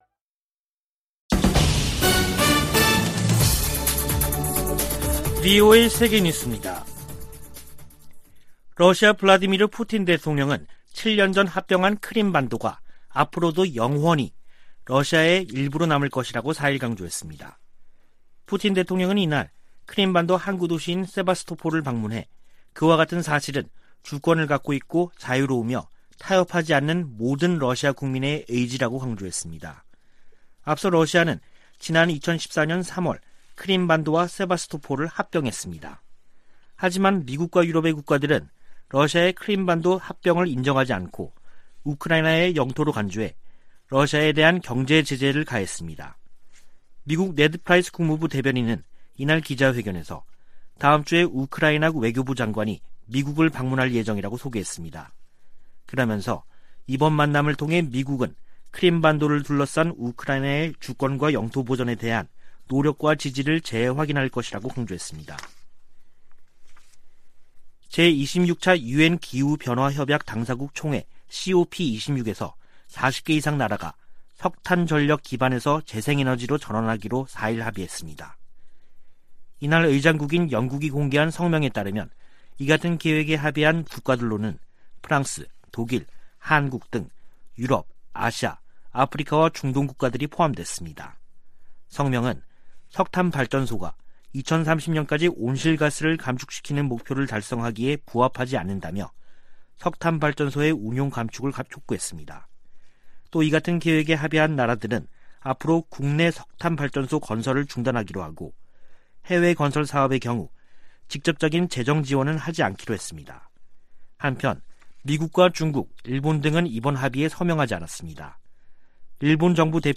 VOA 한국어 간판 뉴스 프로그램 '뉴스 투데이', 2021년 11월 5일 3부 방송입니다. 북한의 불법 무기 프로그램 개발을 막기 위해 전 세계가 유엔 안보리 대북제재를 이행할 것을 미 국무부가 촉구했습니다. 또한 국무부는 북한의 사이버 활동이 전 세계에 위협인 만큼 국제사회 협력이 필수적이라고 밝혔습니다. 뉴질랜드가 안보리 대북제재 위반 활동 감시를 위해 일본 해상에 항공기를 배치합니다.